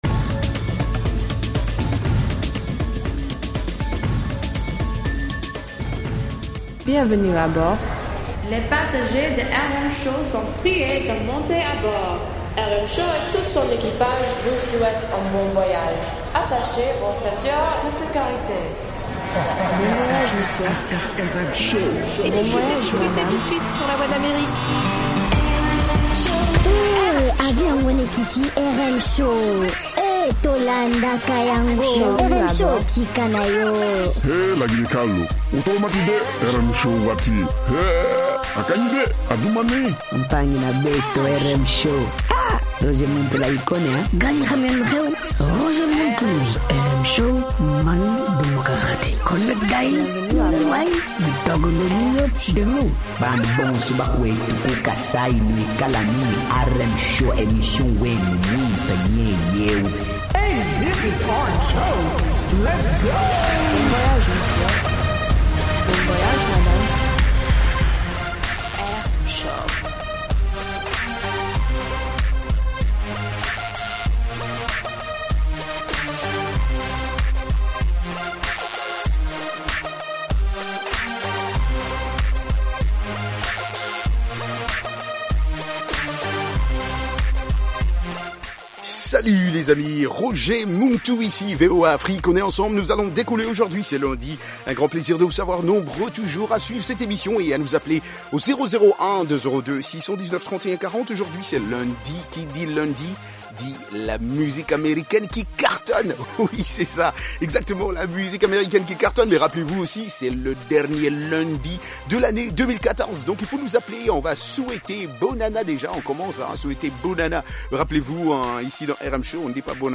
Palmarès des chansons à la mode, en rapport avec les meilleures ventes de disques aux Etats-Unies